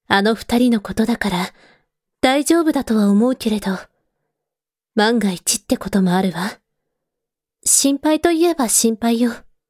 性別：女
穏やかな物言いではあるが、